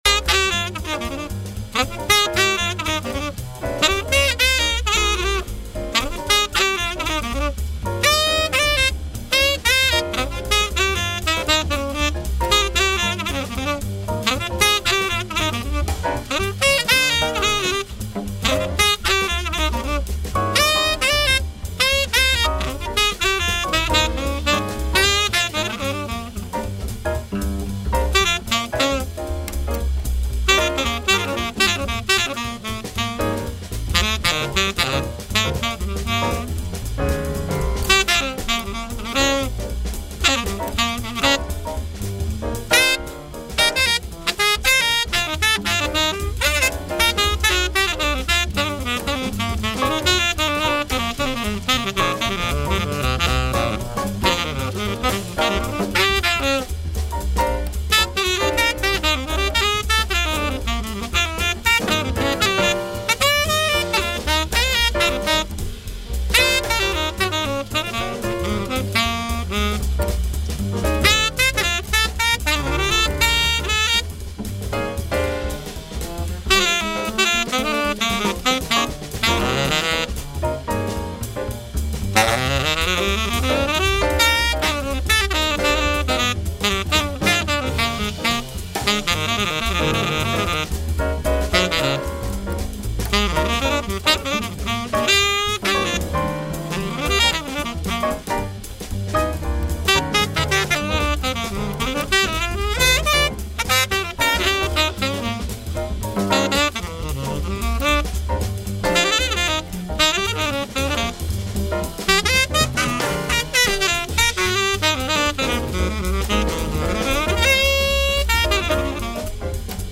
This site provides play-tests of various saxophone models
Saxophone Play-Tests
Click on the button to download a play-test of the Giardinelli GTS-300 Tenor Saxophone and select "Open" or "Save"